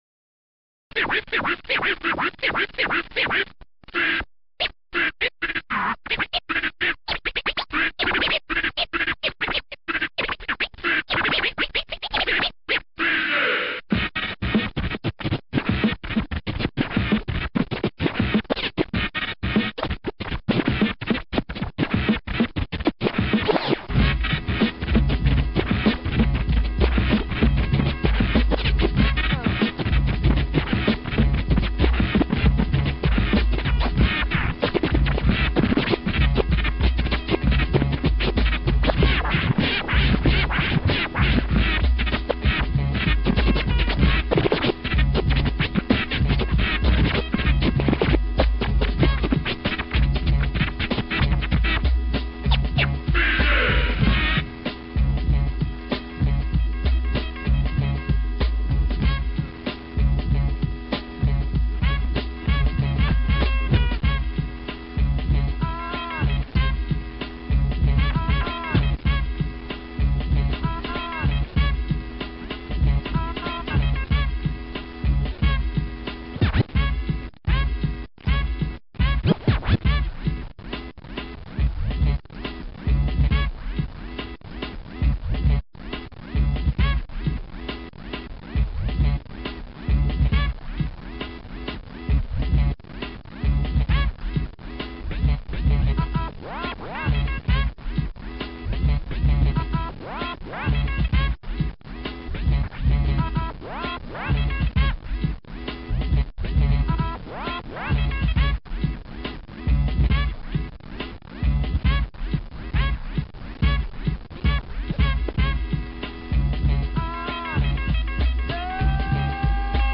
two turntables: